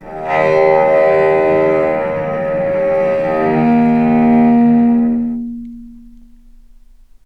vc_sp-D#2-mf.AIF